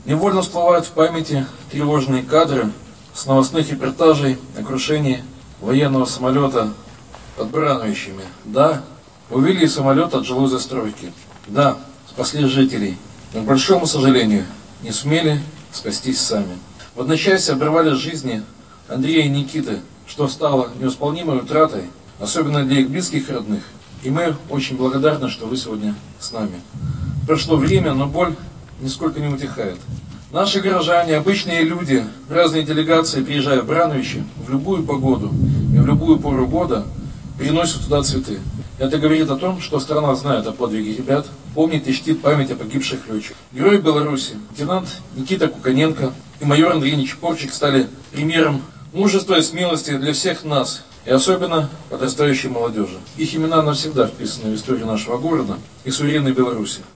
В Барановичах прошел митинг в память о погибших летчиках
Как отметил председатель горисполкома Михаил Баценко, в историю Барановичей этот день навсегда вошел как символ подвига молодых ребят.